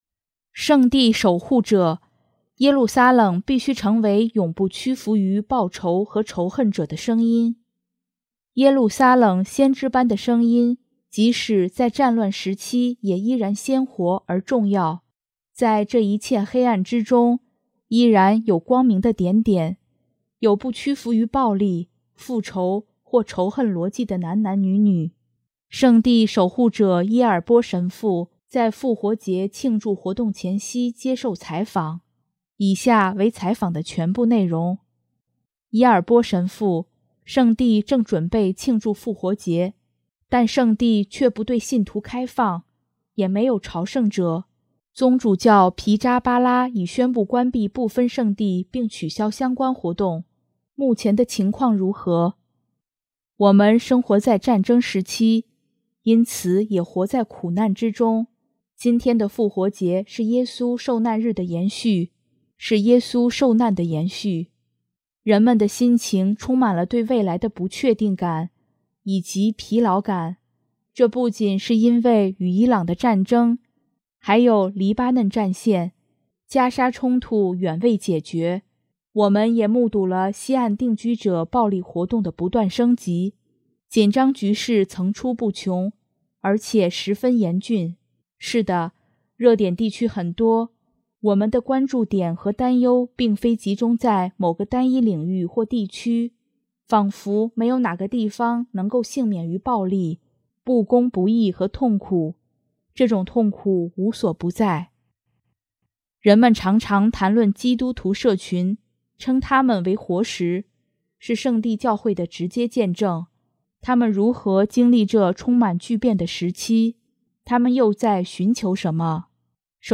圣地守护者伊尔波（Francesco Ielpo）神父在复活节庆祝活动前夕接受采访。